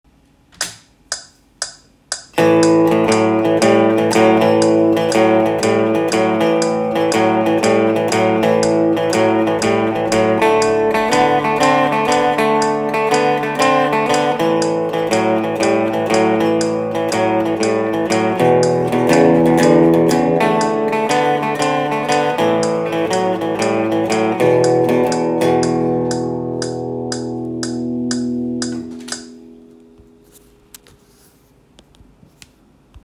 Vrti te note u krug preko pravilnog ritma (bubnjevi ili backing track). Dobit ćeš zanimljive kružne lickove koji zvuče „pravilno“ i melodično.
Rock-n-roll-blues-ritam-primjer-4.m4a